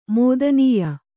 ♪ mōdanīya